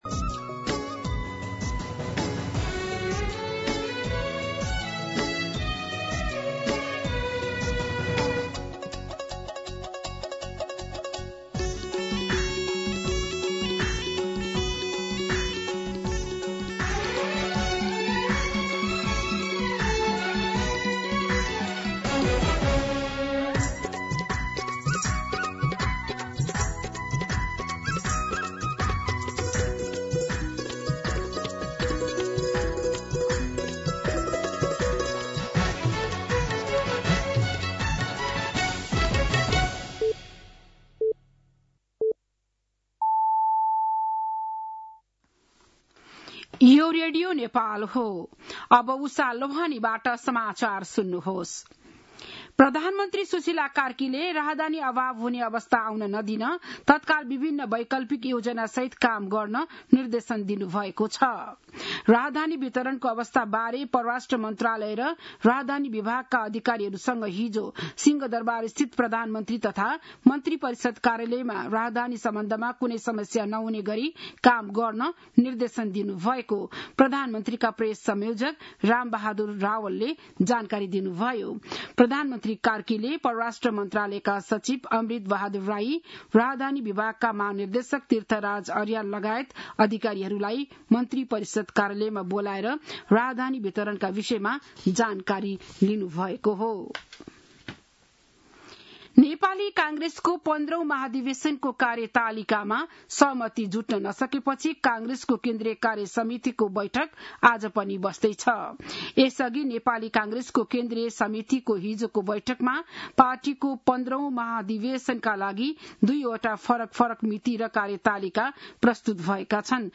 बिहान ११ बजेको नेपाली समाचार : २८ वैशाख , २०८२
11-am-Nepali-News-1.mp3